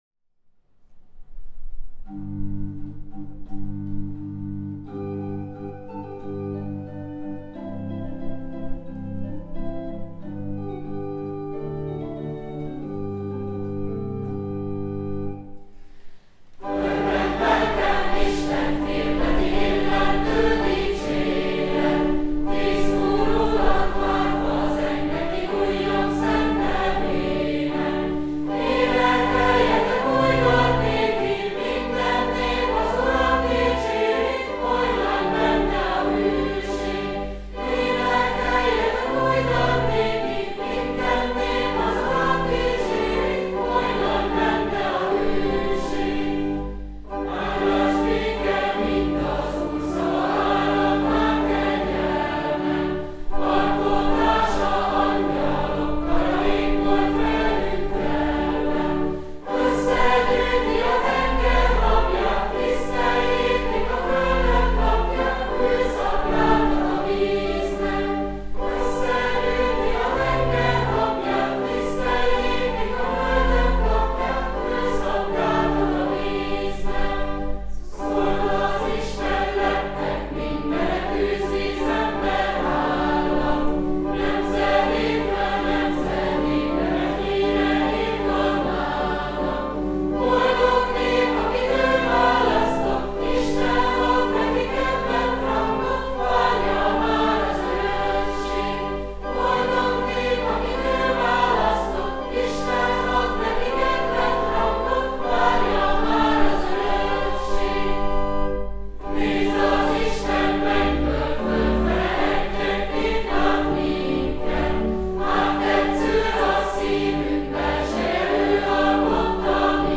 Az ének kottája (PDF) Az ének kottája (PNG) Hangfelvétel Korálkíséret
Nemcsak a szavak ujjongó vidámsága, hanem a ritmusok előrelendítő ereje is átélhetővé teszi azt, hogy Isten és az általa teremtett világ dicsérete más dimenzióba emeli az embert, ahol felismerheti az Úr kegyelmét.